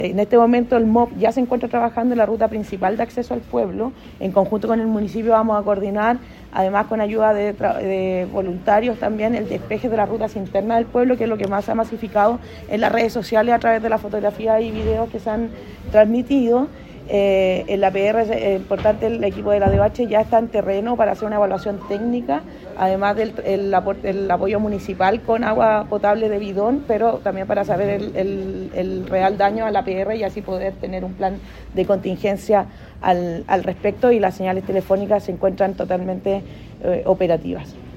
Por su parte, la delegada presidencial (s) de Tarapacá, Camila Castillo, detalló que durante la noche del miércoles se registraron afectaciones en la Ruta 15CH, que fue cerrada provisoriamente, y en el Complejo Fronterizo de Colchane, el cual ya retomó su operación.